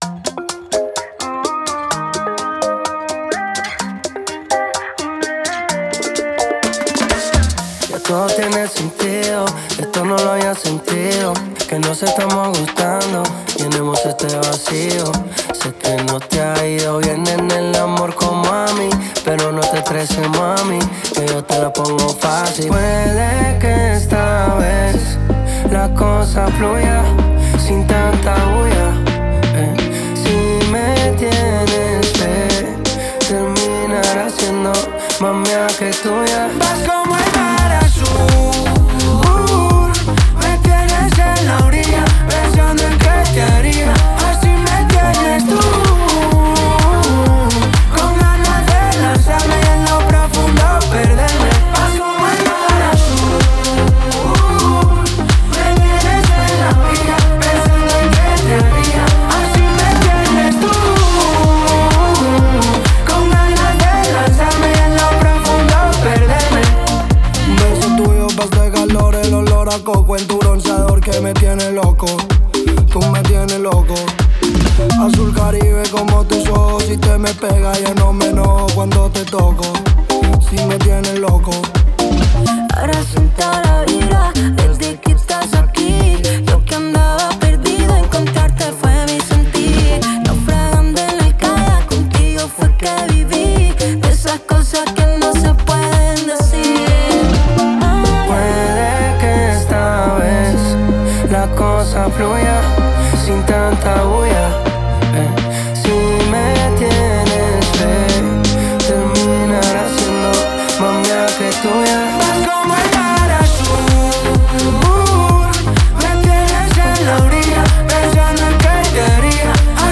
Latin Pop